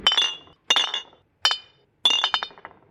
描述：som deduastaçasfazendoum brinde。